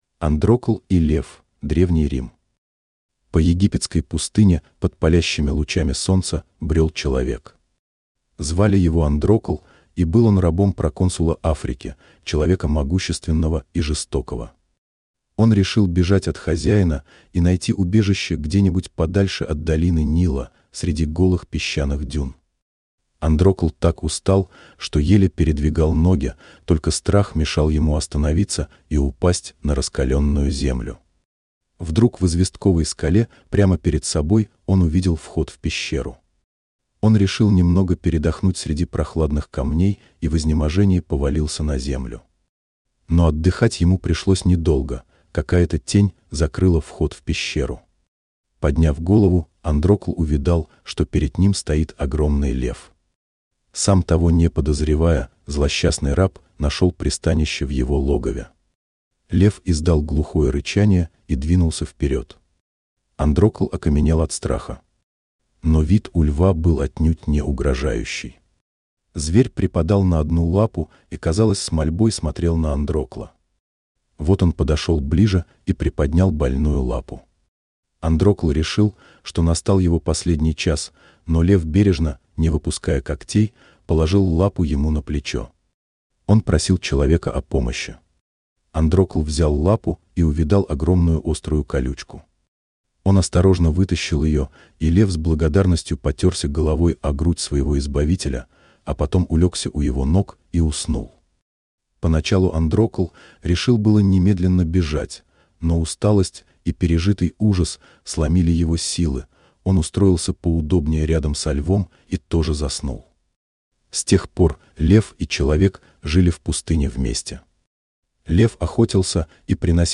Аудио сгенерировано Yandex SpeechKit
post-4435-tts.mp3